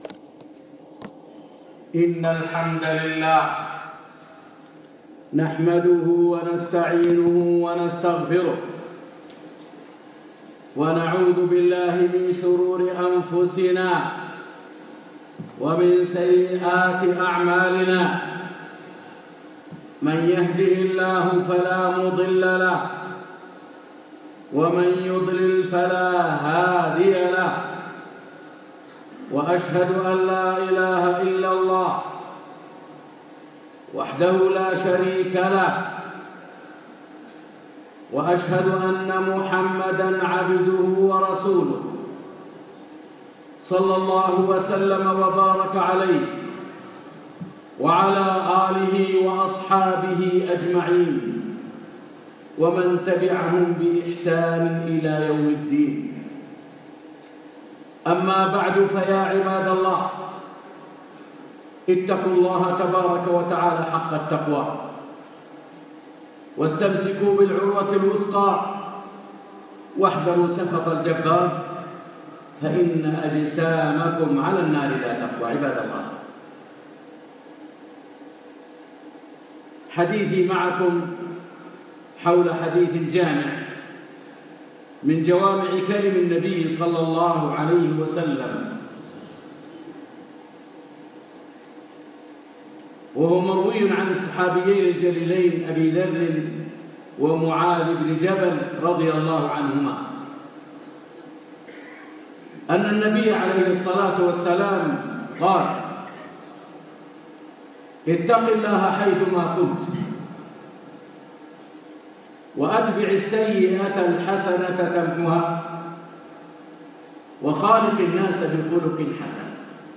مدينة الرس، جامع المنارتين
تقوى الله عز وجل ولزوم الكتاب والسنة - خطبة